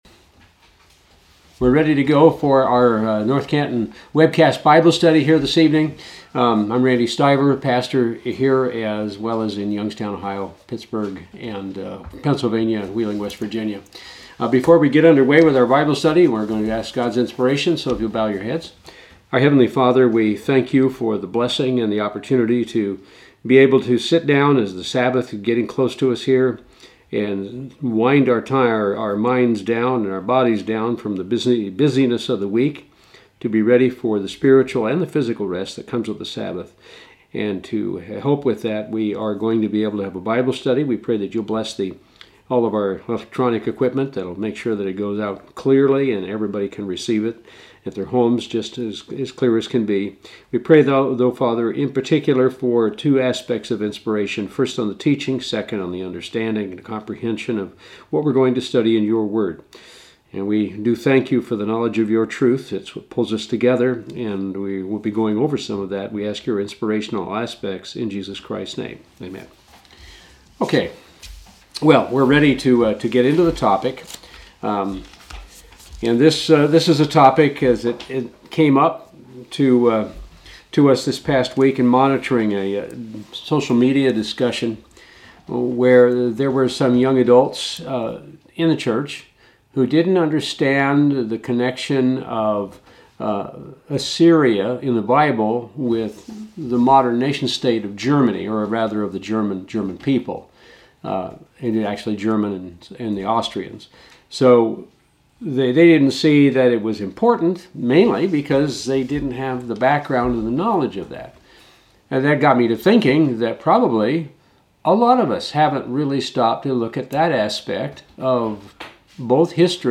The Church of God has long connected ancient Assyria (the empire that took the "lost" 10 tribes of Israel into captivity) with modern-day Germany. Join us for this Bible Study to see how that connection is made.
Given in North Canton, OH